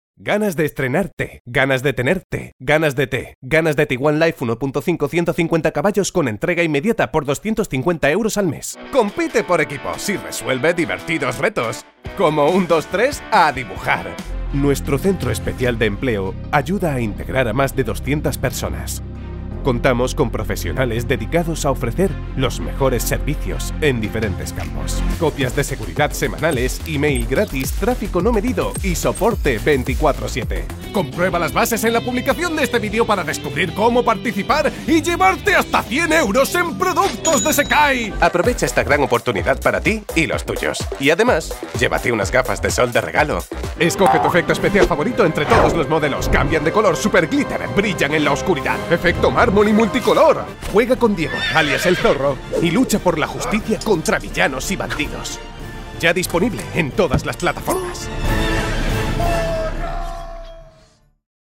Commercial Demo
Baritone